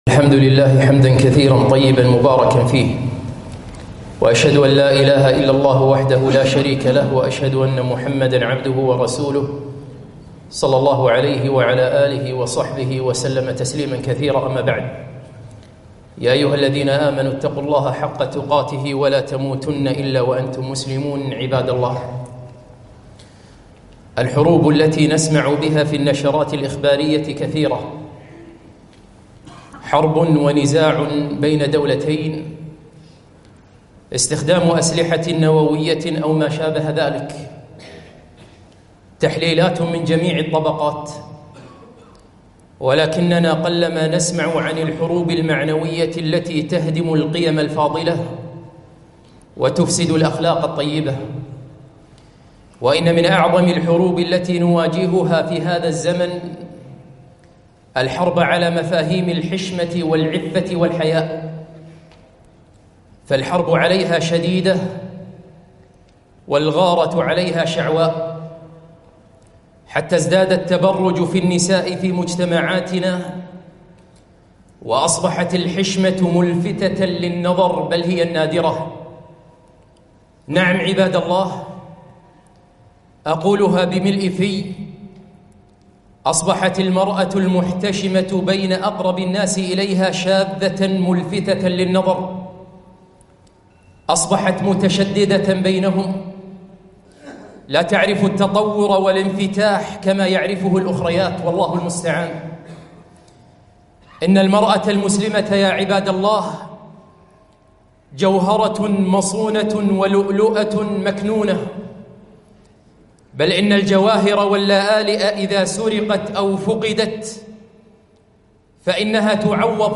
خطبة - رسالة إلى المرأة المتشددة